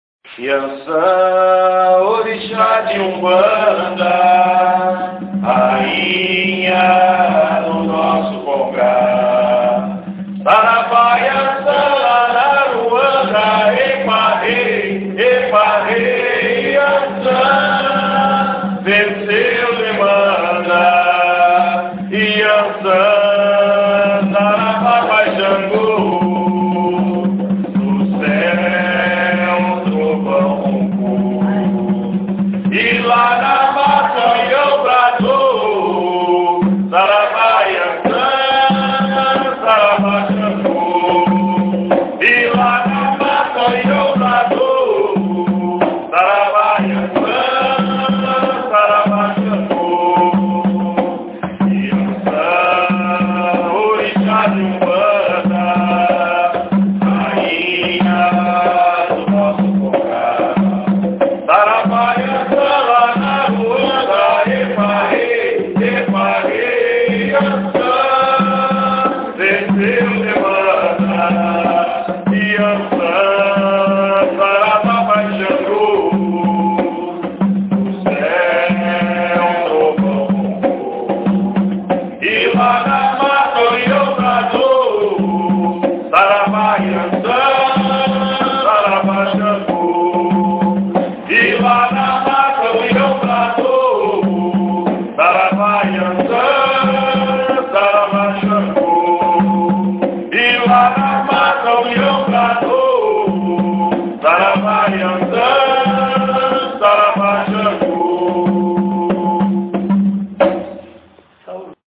Ensaio Gira – Casa Vó Maria